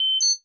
BUTTONS / TONES / CONFIRM1.WAV
CONFIRM1.mp3